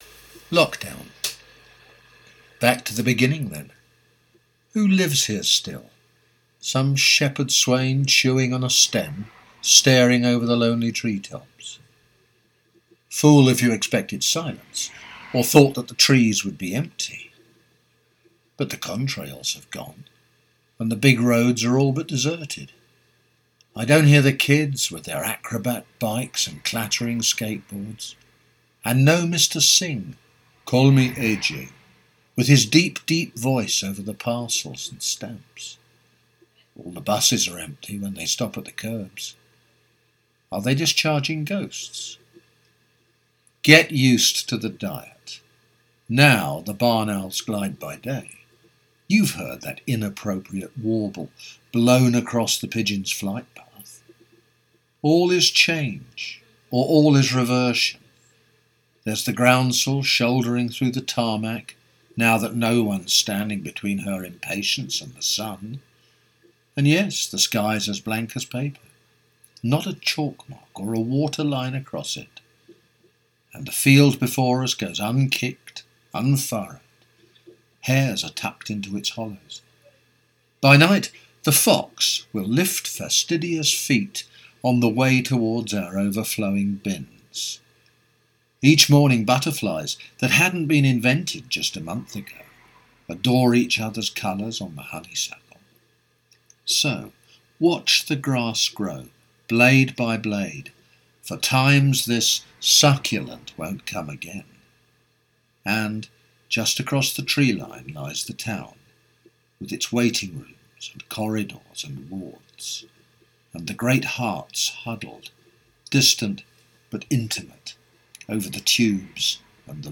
violin, viola, vocals and video creation
guitar.
Listening to your recordings it sounds like you have a similar bird soundscape to us.